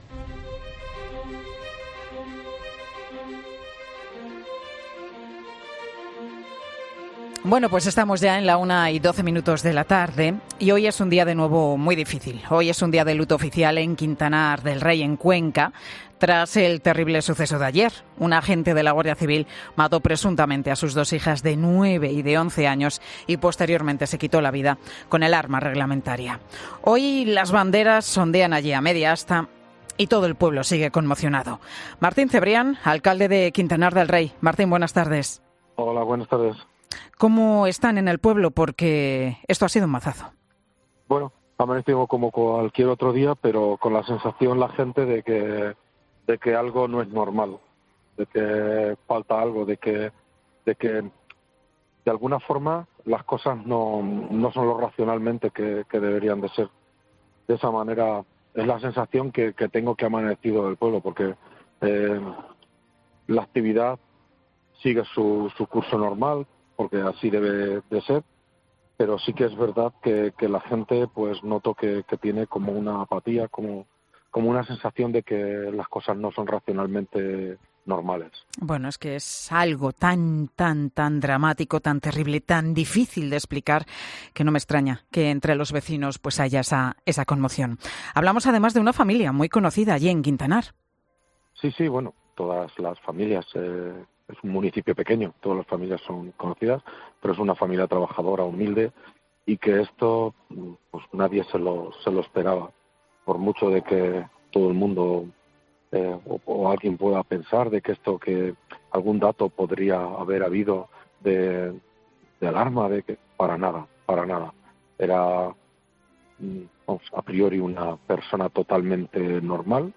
Alcalde de Quintanar del Rey en 'Mediodía COPE': "El padre, trabajador y humilde, no lo puede asimilar"
"El día ha amanecido como cualquier otro día, pero con la sensación entre la gente de que algo no es normal, de que falta algo, de que las cosas no son lo racionalmente que deben de ser. La actividad sigue su curso normal, así debe ser, pero noto que la gente tiene apatía, de que las cosas no son racionalmente normales", dice en Mediodía COPE el alcalde de Quintanar del Rey Martín Cebrián.